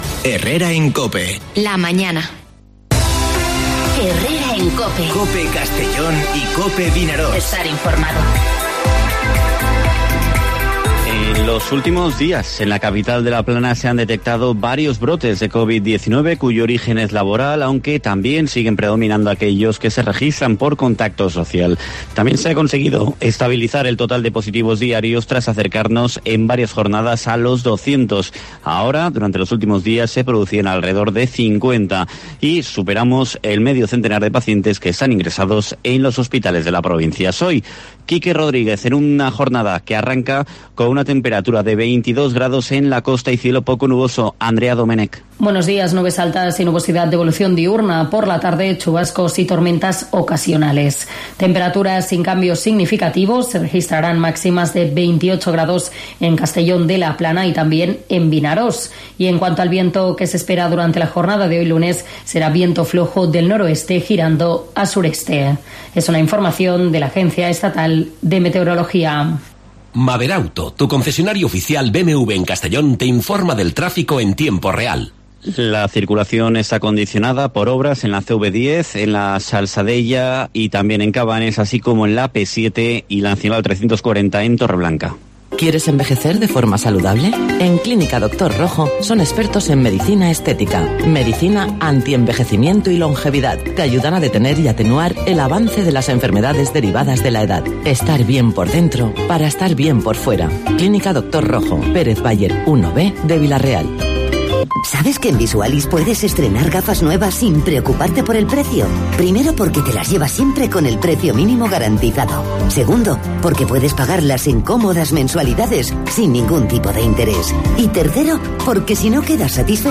Informativo Herrera en COPE en la provincia de Castellón (21/09/2020)